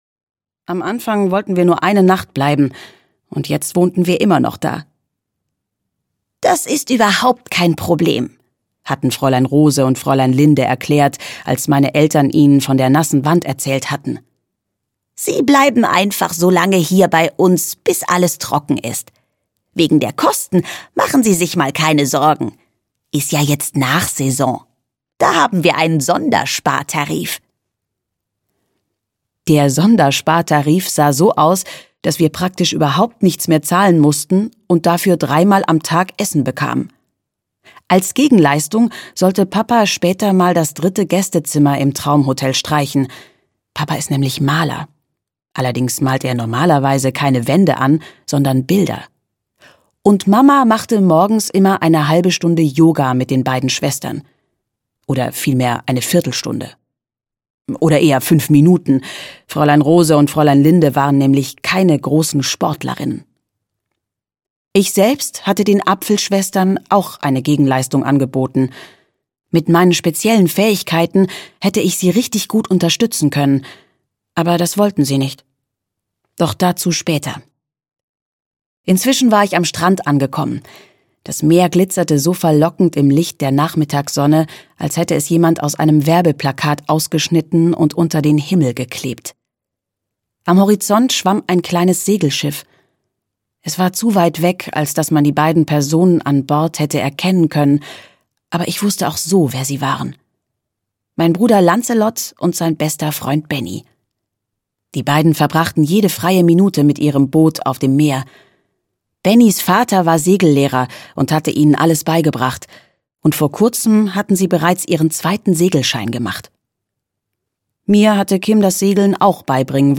Die goldene Botschaft (Das Hotel der verzauberten Träume 3) - Gina Mayer - Hörbuch